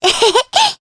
Cleo-Vox_Happy2_jp.wav